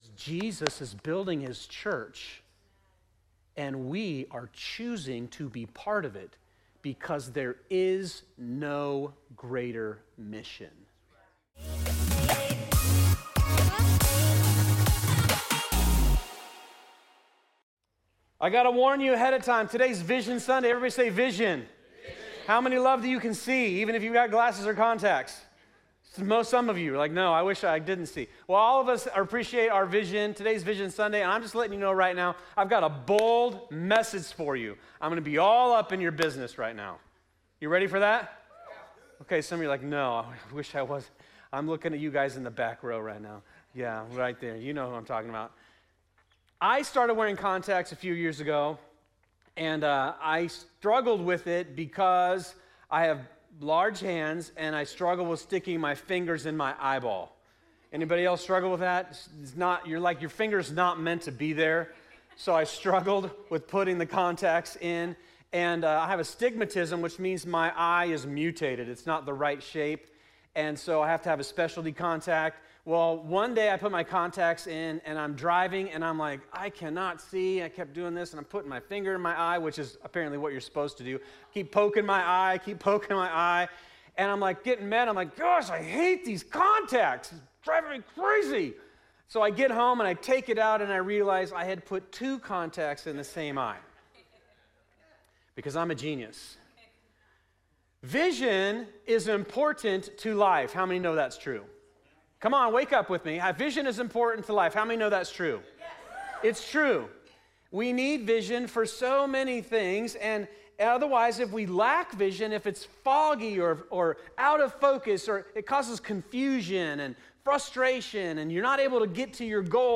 Vision-Sunday.mp3